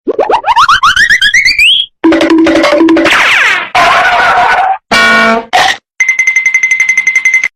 cartoon sound effects
cartoon-sound-effects.mp3